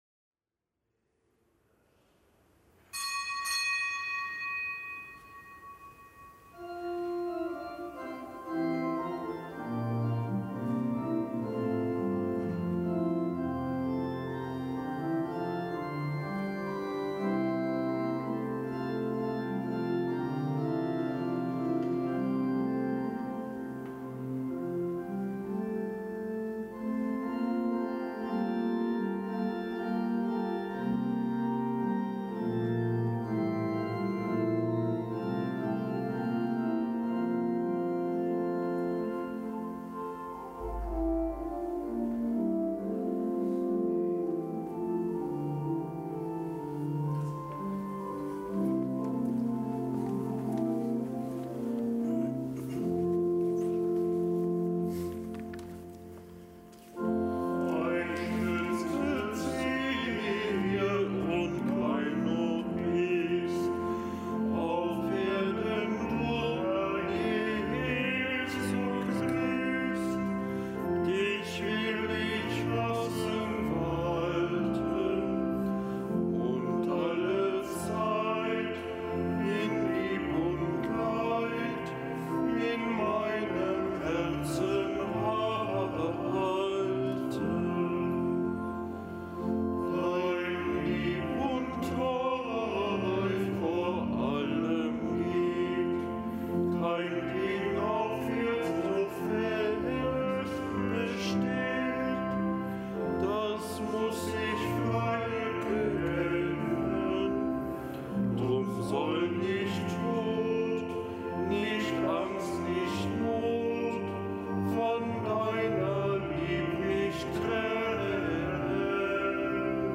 Kapitelsmesse aus dem Kölner Dom am Donnerstag der vierten Woche im Jahreskreis.